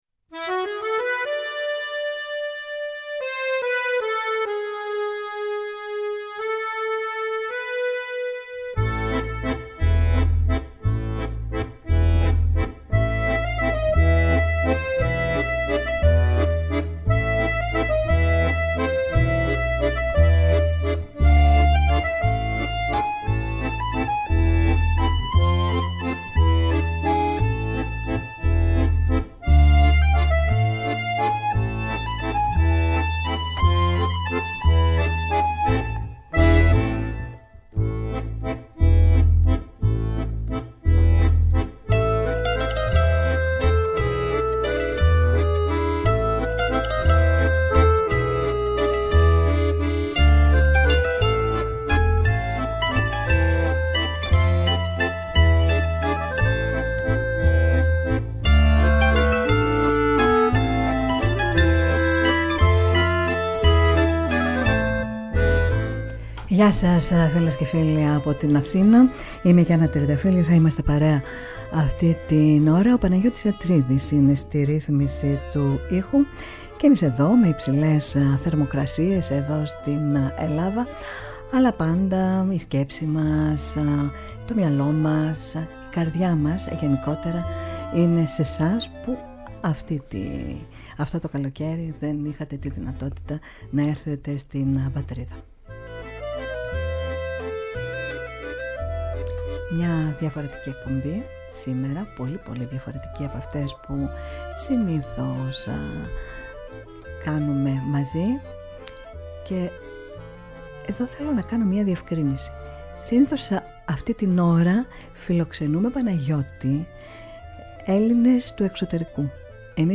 Στην εκπομπή μεταδόθηκαν τραγούδια του από τις συναυλίες του στην Ελλάδα και στο εξωτερικό.